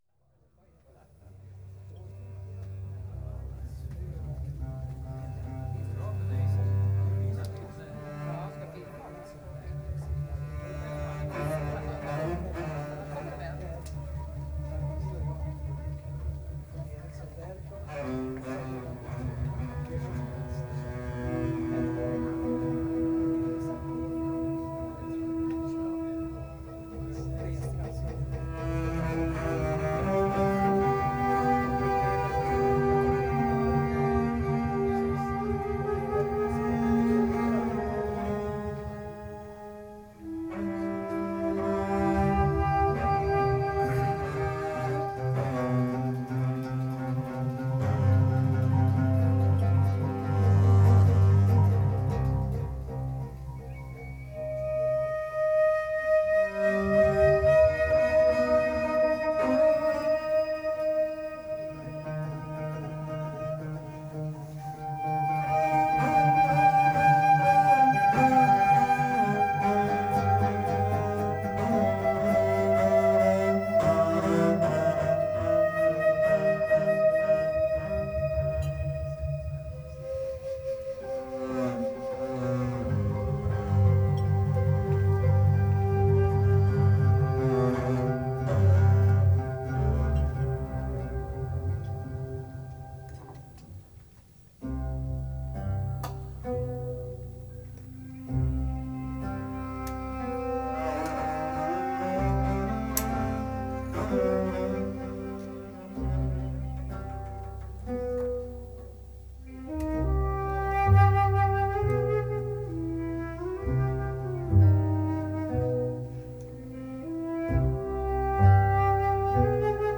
Improvisation jazz världsmusik kammarmusik